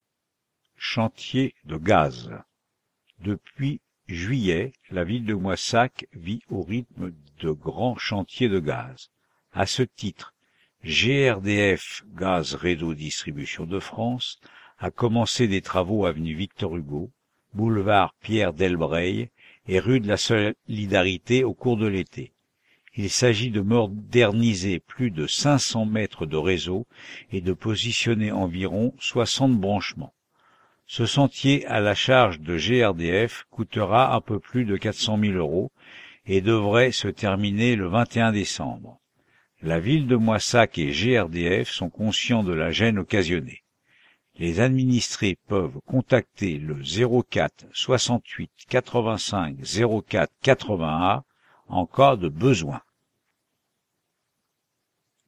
P04 03 Chantier De Gaz
p04-03-chantier-de-gaz.mp3